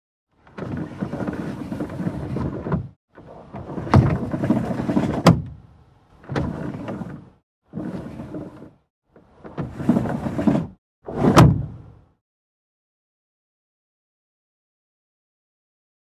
Car Window Roll Up And Down; Manual Hand Crank Style, Close Perspective.